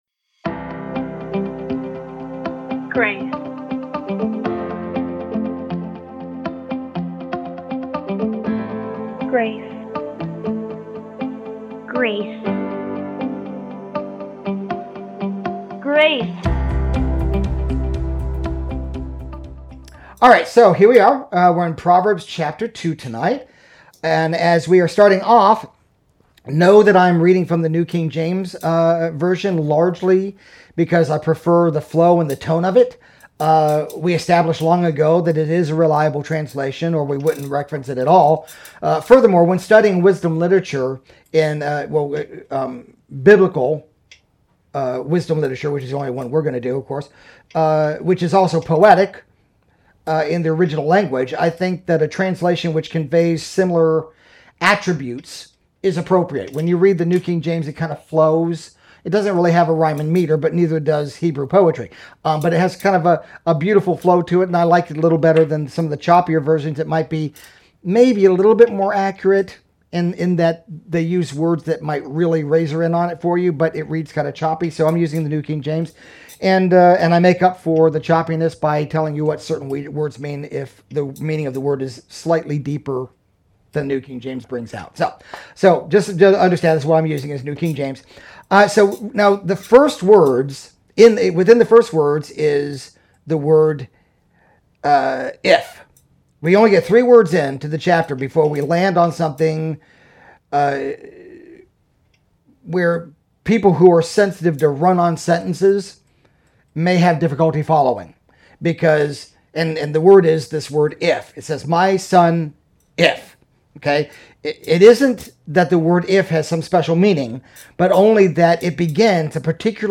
NOTE: About 40 min. into the audio, the sound gets pretty bad. Our good recorder stopped working and I had to use audio from a phone on Facebook LIVE, so it is not so good.